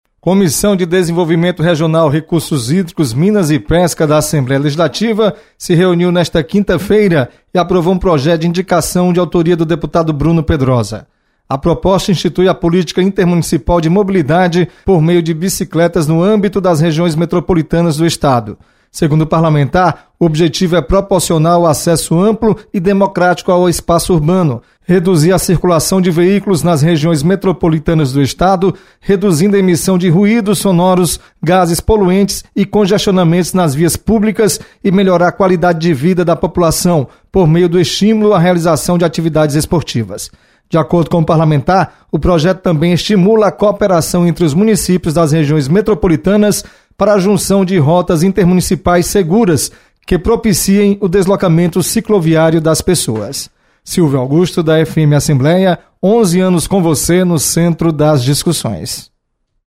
Você está aqui: Início Comunicação Rádio FM Assembleia Notícias Comissões